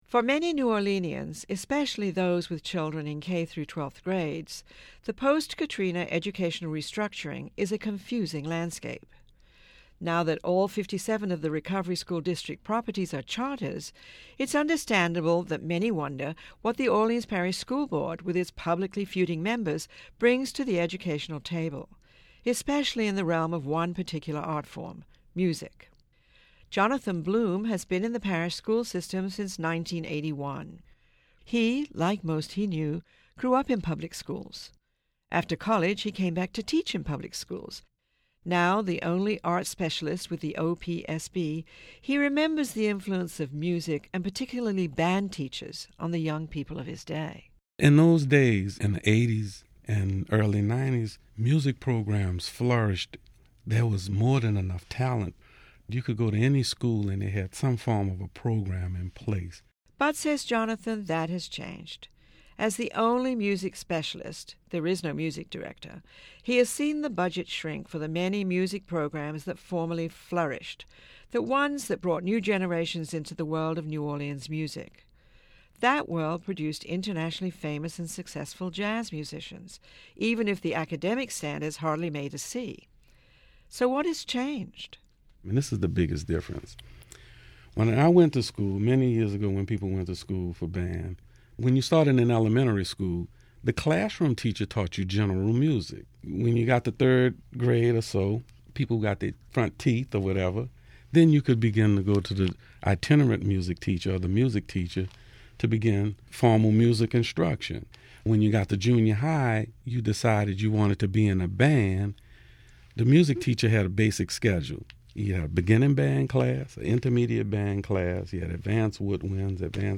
Voices from the Classroom: The Arts in Education Reform is a year-long series about the arts and education in New Orleans by NolaVie and its cultural partner, WWNO public radio.